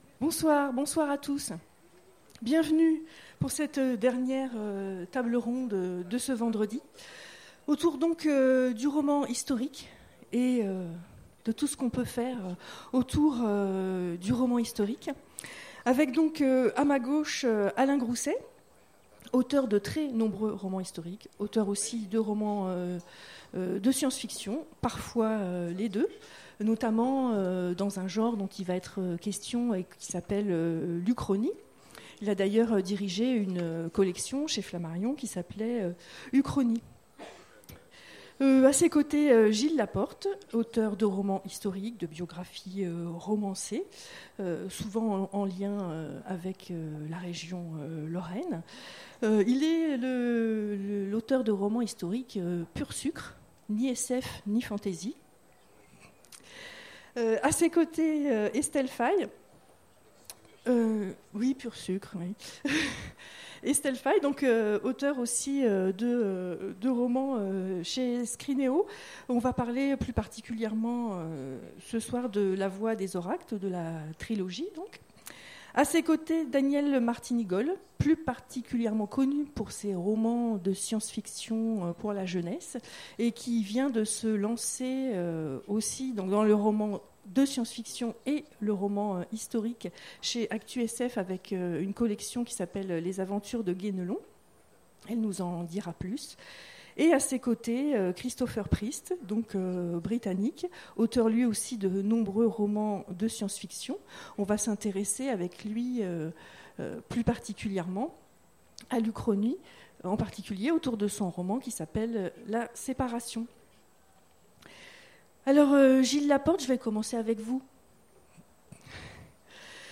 Imaginales 2016 : Conférence Le roman historique